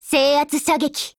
贡献 ） 协议：Copyright，其他分类： 分类:少女前线:史蒂文斯520 、 分类:语音 您不可以覆盖此文件。
Stevens520_ATTACK_JP.wav